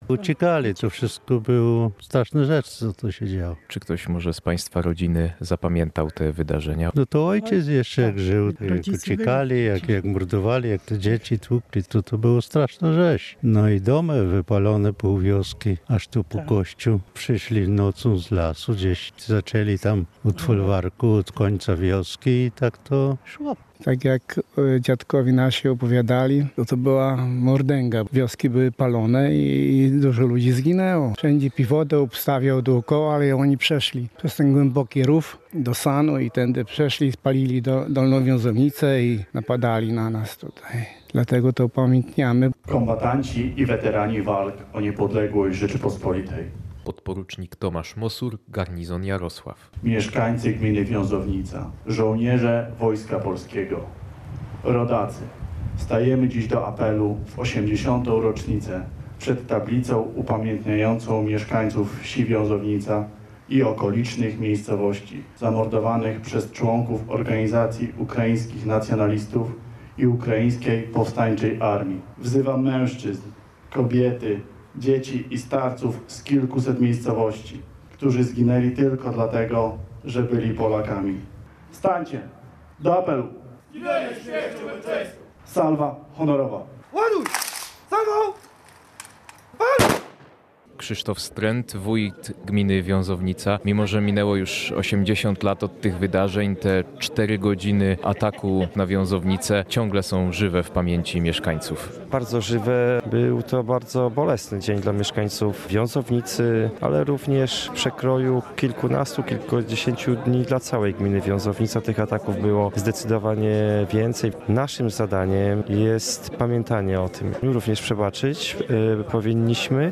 – mówił Krzysztof Strent, wójt gminy Wiązownica podczas obchodów 80. rocznicy spalenia Wiązownicy przez pododdział UPA.